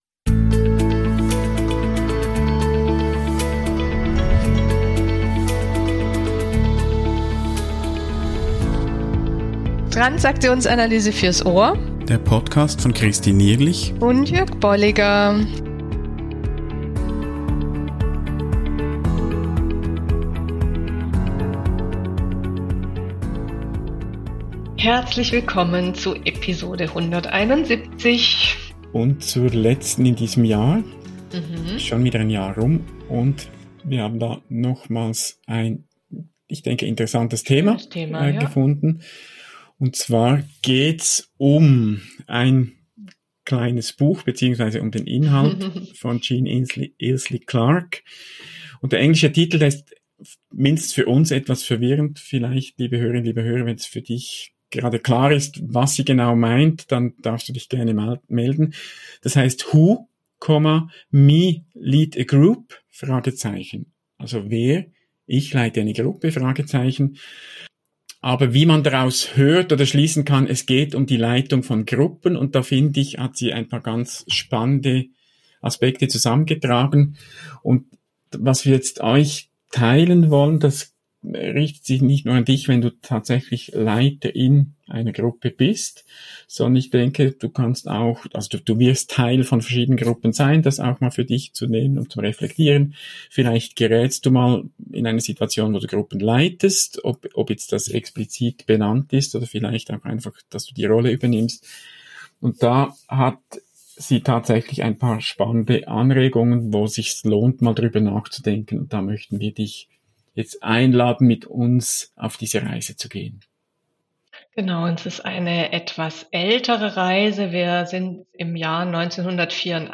Gespräche über Modelle und Konzepte der Transaktionsanalyse und deren Anwendung